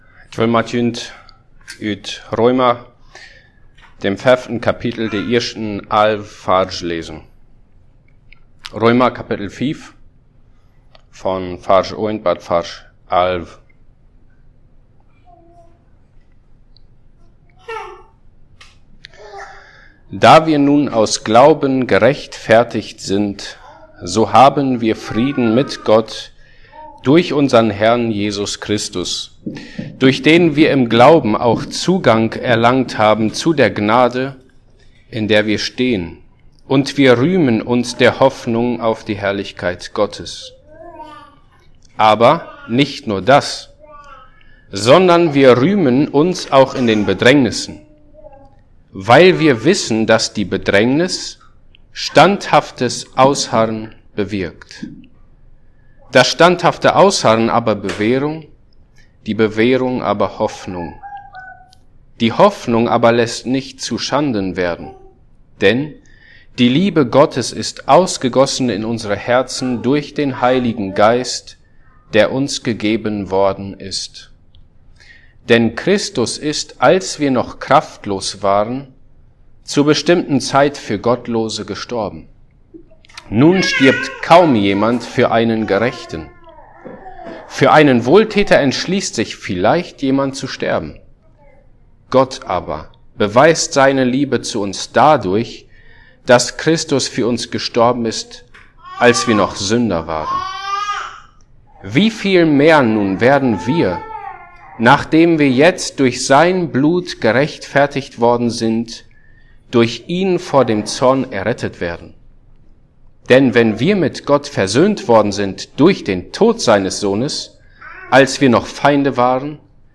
Passage: Romans 5:1-11 Service Type: Sunday